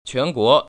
[quánguó] 취앤구어